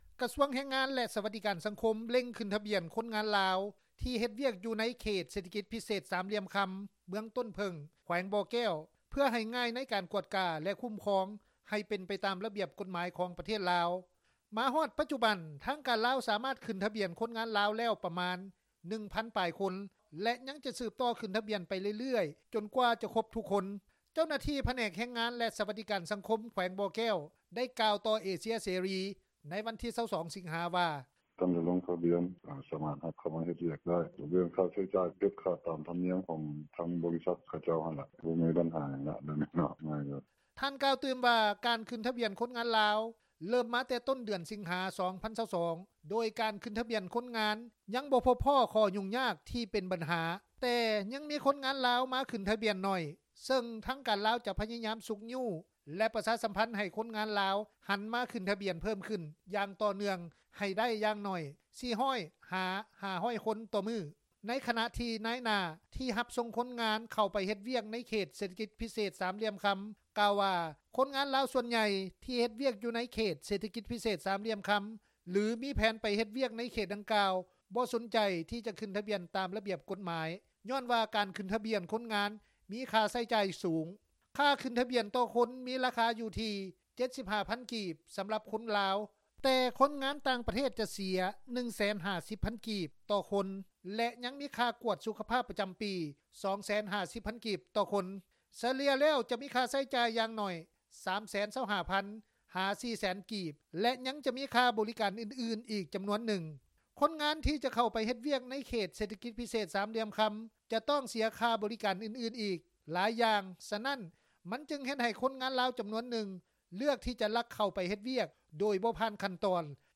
ນາຍໜ້າຮັບ-ສົ່ງຄົນງານ ເຂົ້າໄປເຮັດວຽກໃນເຂດເສຖກິຈ ພິເສດສາມຫລ່ຽມຄຳ ກ່າວຕໍ່ວິທຍຸເອເຊັຽເສຣີ ໃນວັນທີ 22 ສິງຫາ ວ່າ: